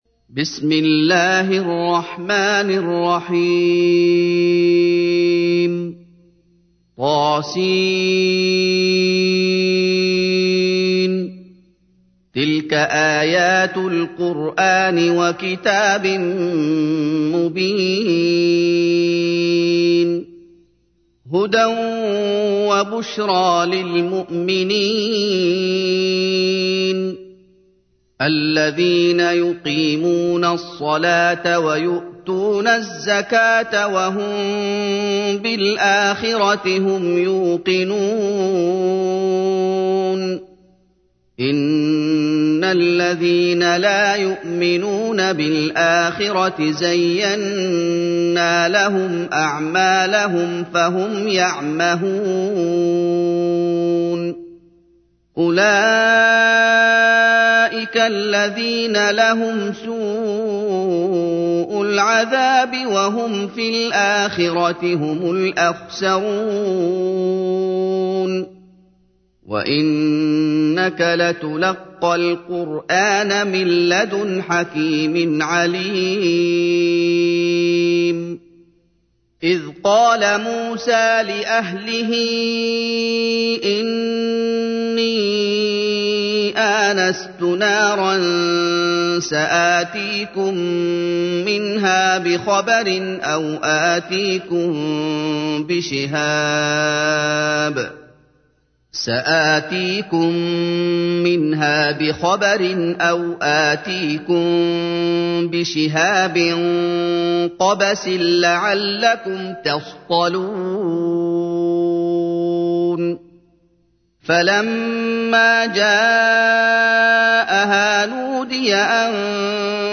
تحميل : 27. سورة النمل / القارئ محمد أيوب / القرآن الكريم / موقع يا حسين